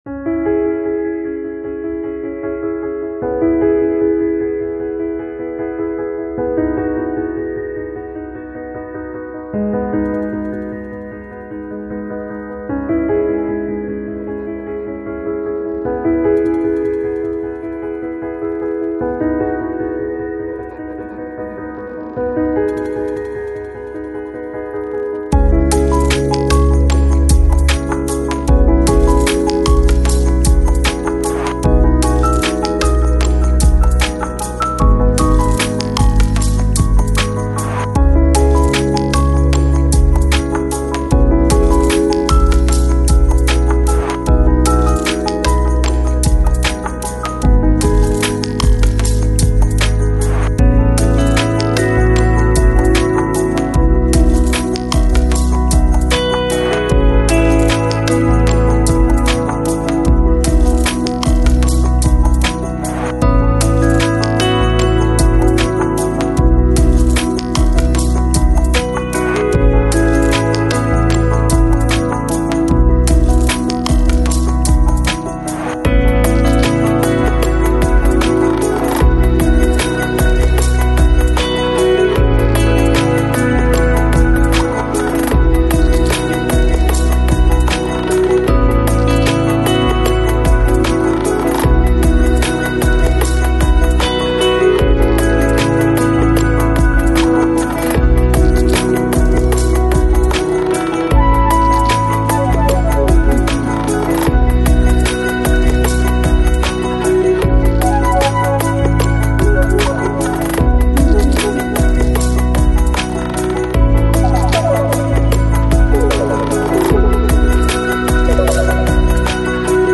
Lo-Fi, Lounge, Chillout Год издания